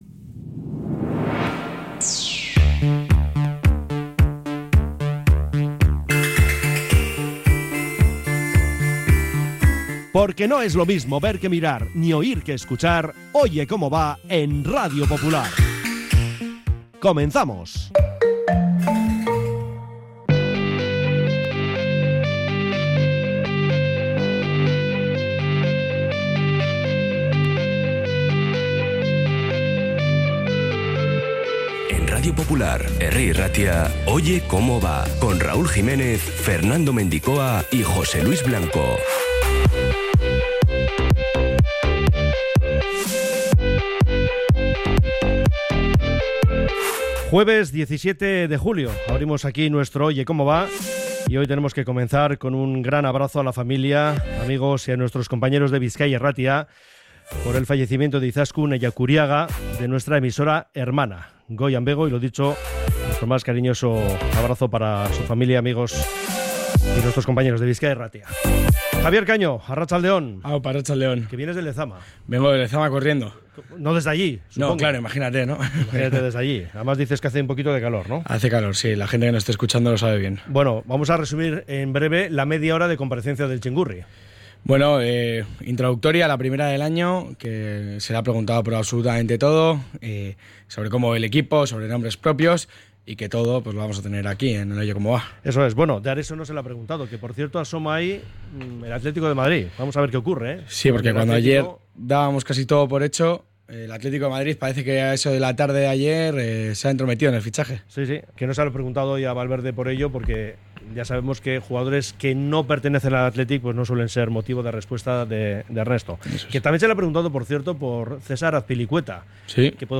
Oye Cómo Va 17-07-25 | Rueda de prensa de Valverde